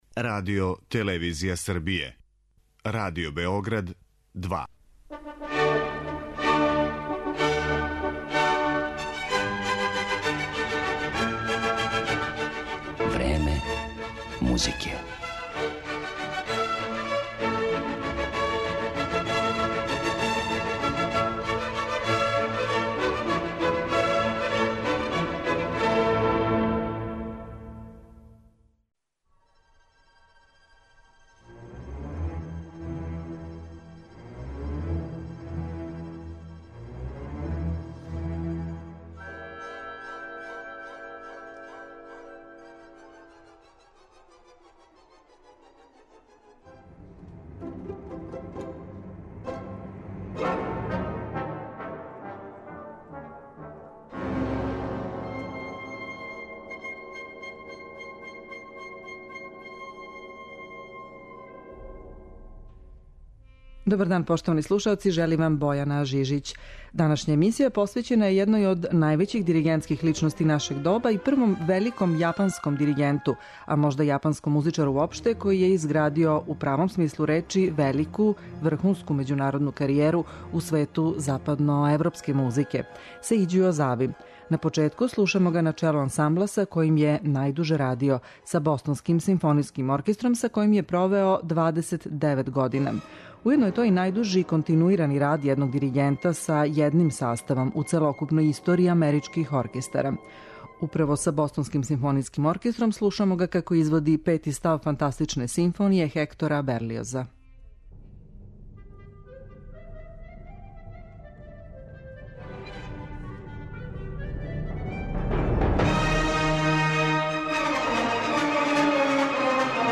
Слушаћемо га са ансамблом на чијем је челу био 29 година - Бостонским симфонијским оркестром, али и са Саито Кинен оркестром, Чикашким симфонијским оркестром, Берлинском и Бечком филхармонијом.